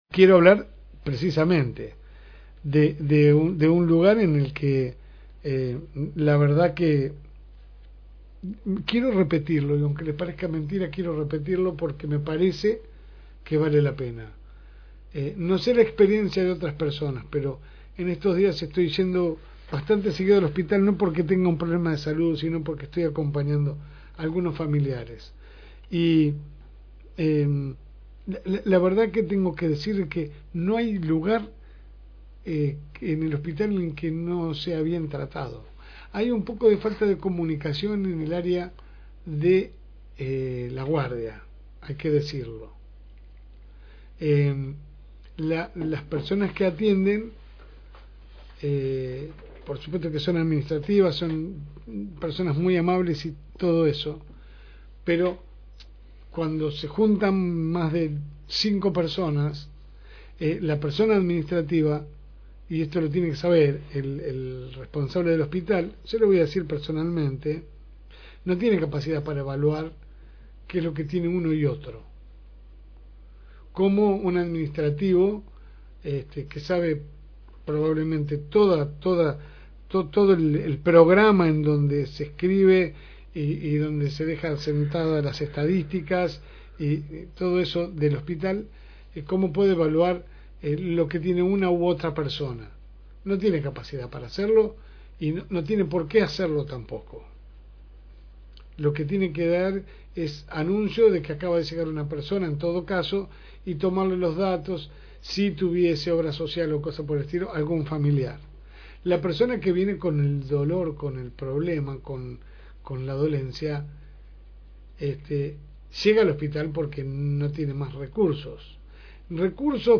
AUDIO – EDITORIAL – «ESPERE AHI SENTADO»